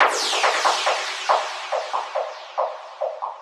FX_Gunshot.wav